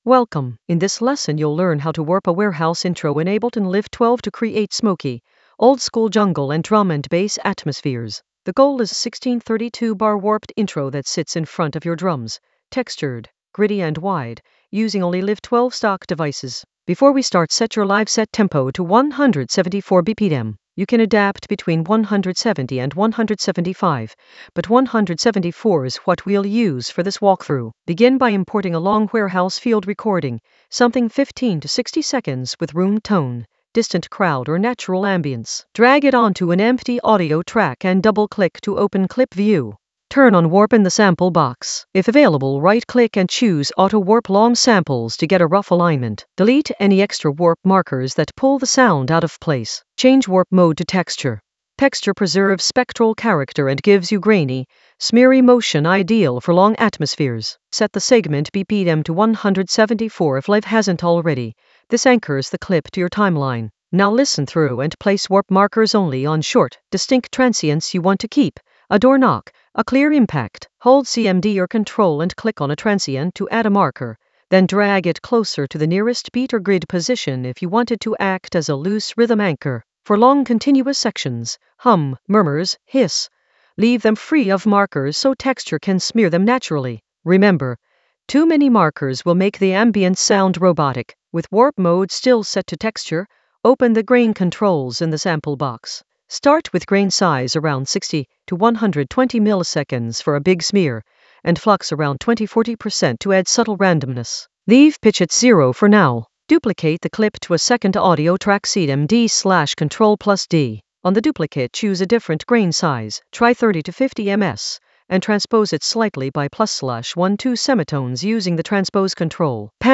An AI-generated beginner Ableton lesson focused on Warp a warehouse intro for smoky warehouse vibes in Ableton Live 12 for jungle oldskool DnB vibes in the Atmospheres area of drum and bass production.
Narrated lesson audio
The voice track includes the tutorial plus extra teacher commentary.